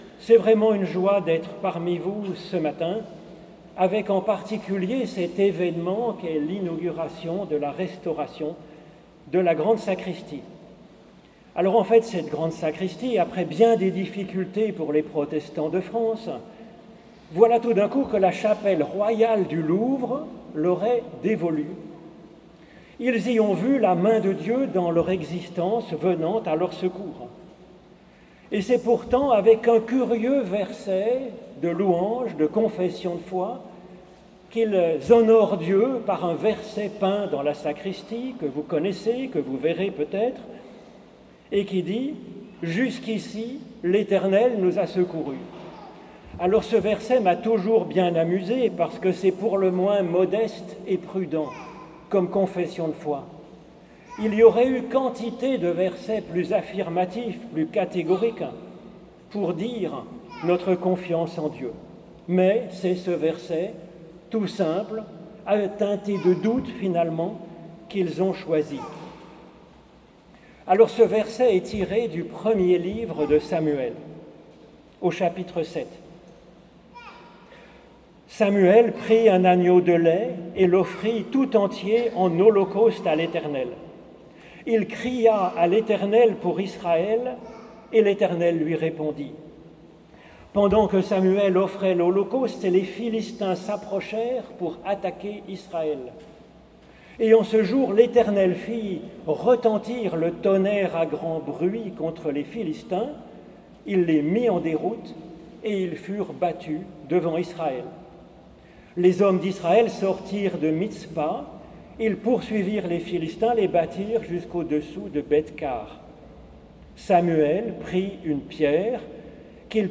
Culte au temple de l'Oratoire du Louvre à Paris
prédication